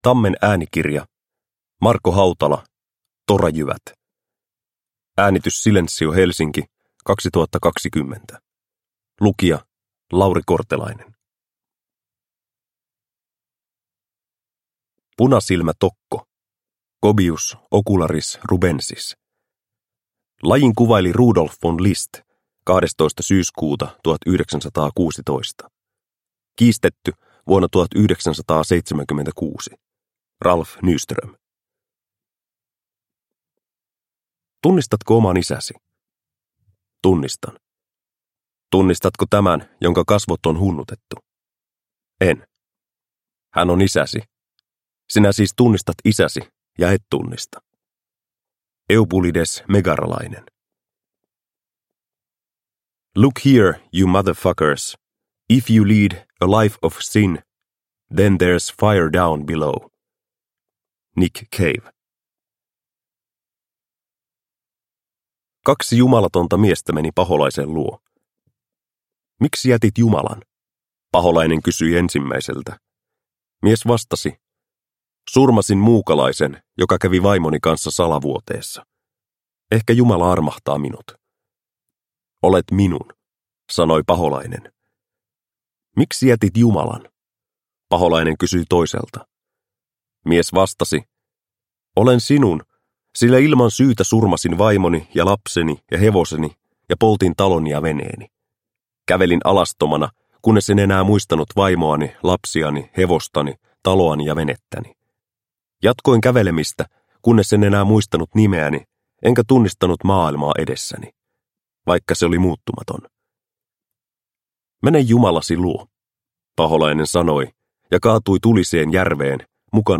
Torajyvät – Ljudbok – Laddas ner